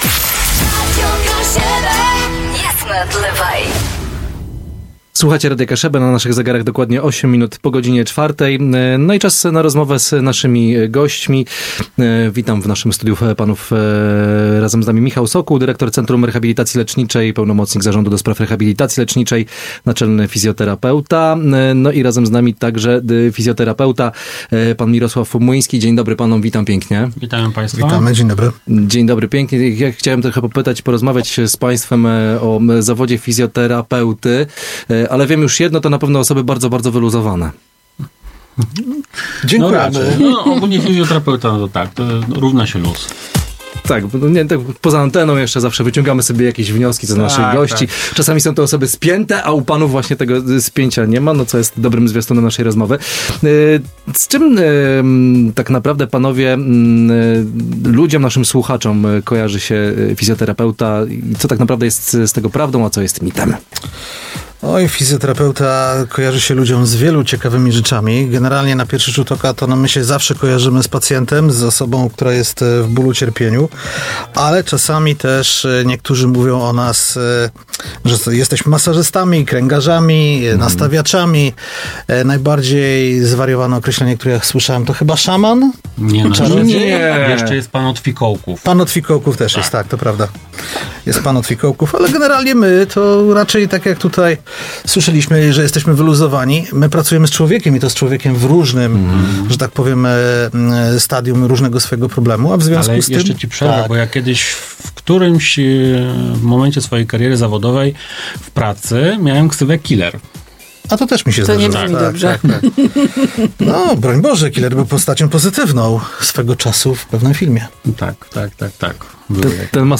Eksperci z uśmiechem wspominali określenia takie jak „pan od fikołków”, „szaman” czy „killer”, zaznaczając przy tym, że ich praca to przede wszystkim zaawansowana diagnostyka funkcjonalna.